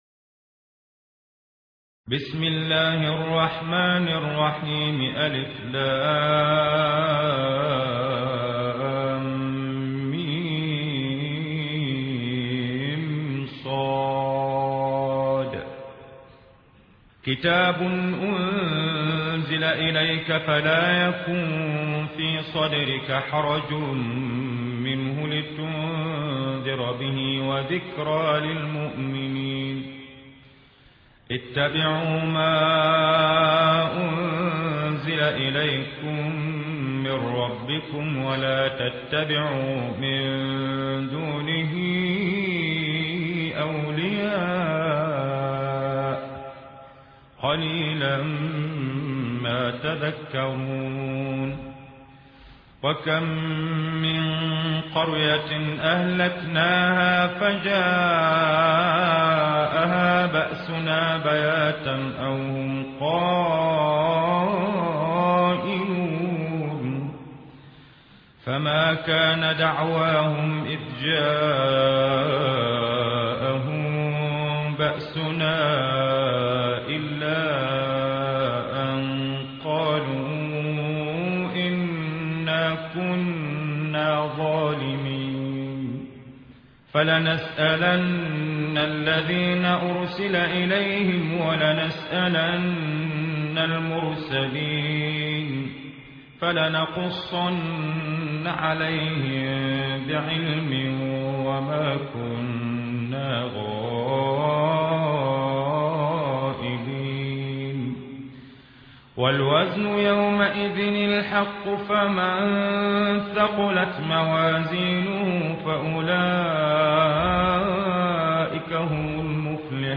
قرآن